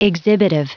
Prononciation du mot exhibitive en anglais (fichier audio)
Prononciation du mot : exhibitive